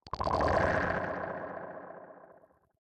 Minecraft Version Minecraft Version latest Latest Release | Latest Snapshot latest / assets / minecraft / sounds / block / conduit / attack2.ogg Compare With Compare With Latest Release | Latest Snapshot